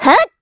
Ripine-Vox_Attack3_kr.wav